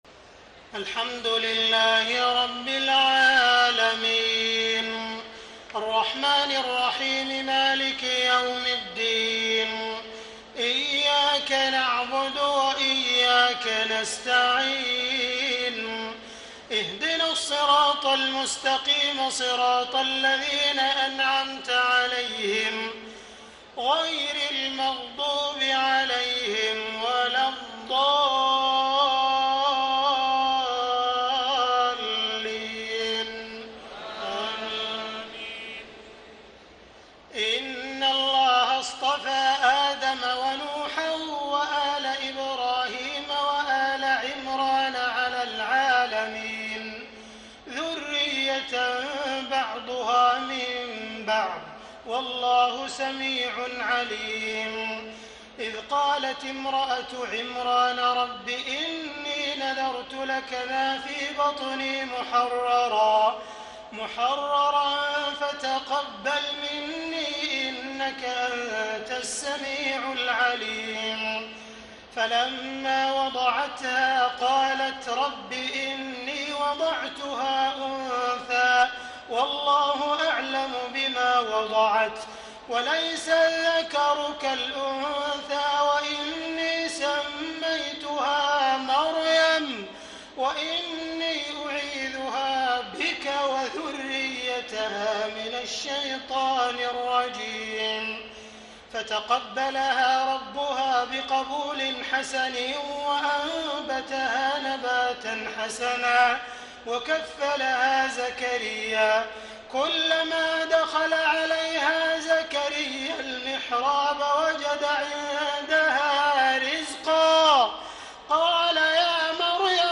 تهجد ليلة 23 رمضان 1434هـ من سورة آل عمران (33-92) Tahajjud 23 st night Ramadan 1434H from Surah Aal-i-Imraan > تراويح الحرم المكي عام 1434 🕋 > التراويح - تلاوات الحرمين